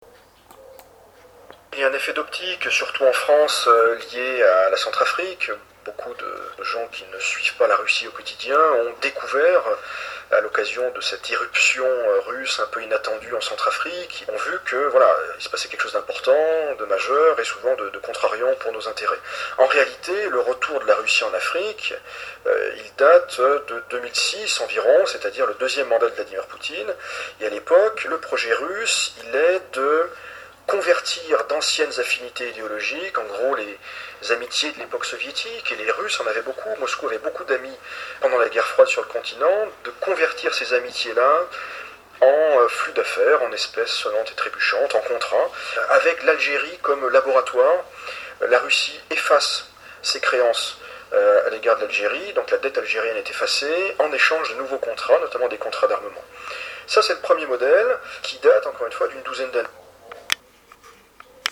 PODCASTS – RADIOTAMTAM AFRICA Radio TAMTAM AFRICA À Sotchi, sur les bords de la mer Noire Reportage -RadioTamTam 25 octobre 2019
Avec nos envoyés spéciaux à Sotchi,